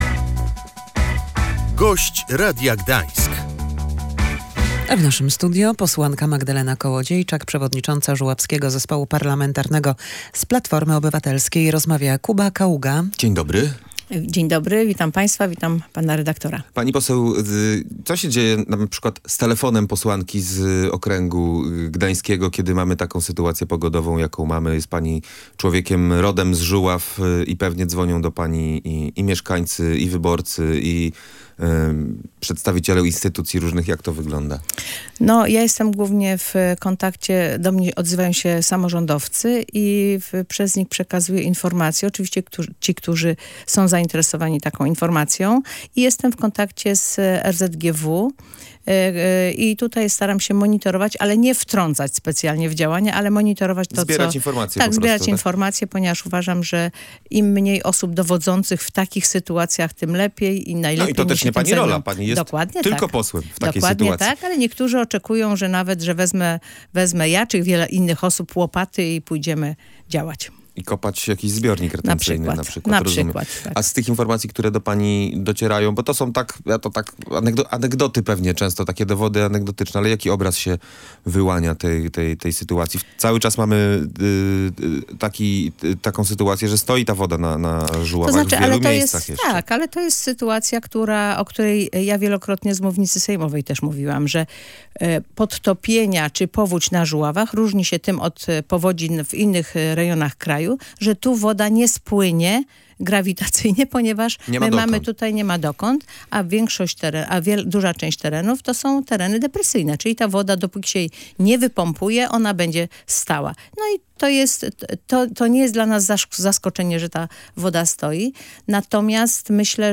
Po poniedziałkowej ulewie te tereny nadal walczą z podtopieniami. Jak mówiła w Radiu Gdańsk posłanka Platformy Obywatelskiej, z unijnych środków dla Żuław zabezpieczono 420 milionów złotych na inwestycje.
Pieniądze dla Żuław z unijnego programu muszą być wydane do 2029 roku. Posłuchaj całej rozmowy: https